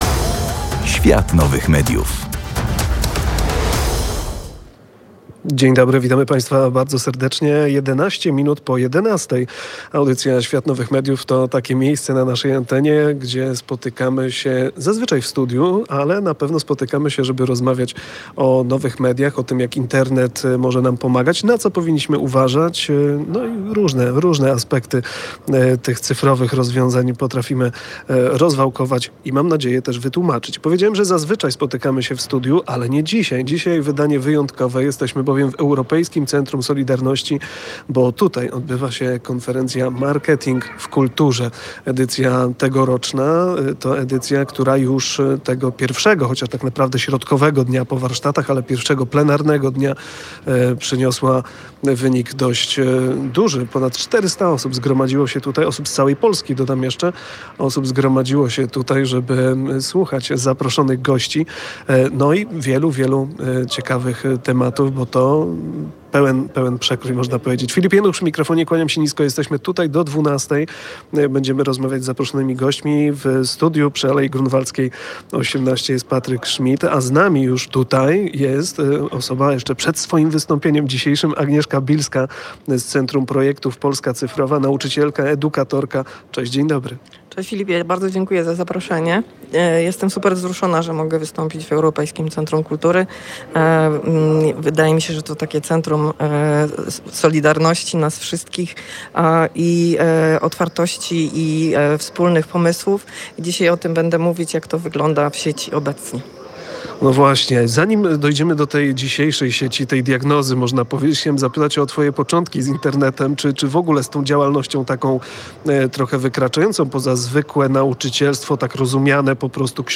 Tym razem w audycji „Świat Nowych Mediów” byliśmy w terenie. Nadawaliśmy z 12. edycji konferencji Marketing w Kulturze, która odbywała się w Europejskim Centrum Solidarności.
Udało nam się porozmawiać z dwiema prelegentkami.